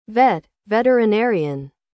21. vet (veterinarian) /vet/: bác sĩ thú y